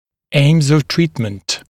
[eɪmz əv ‘triːtmənt][эймз ов ‘три:тмэнт]цели лечения